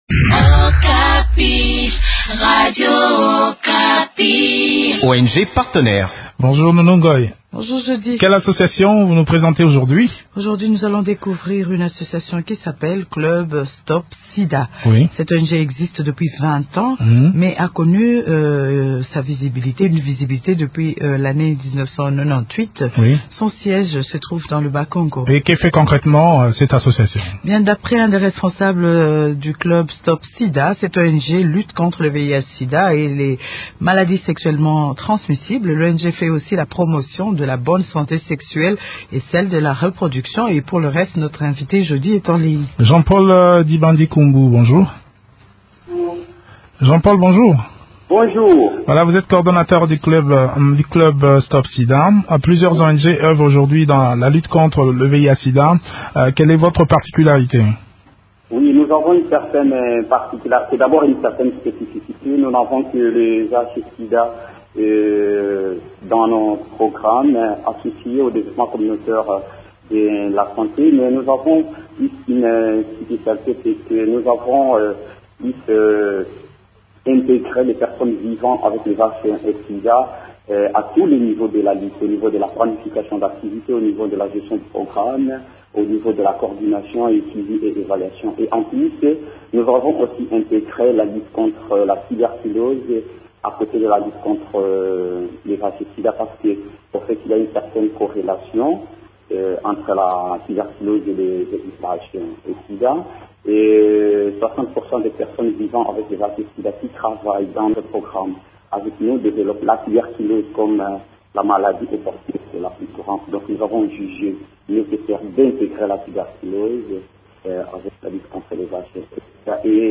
Depuis sa création, cette ONG sensibilise la population sur le VIH/SIDA et les infections sexuellement transmissibles, notamment, par des campagnes dans les écoles, les marchés et autres endroits publics. Découvrons l’essentiel des activités de cette ONG dans cet entretien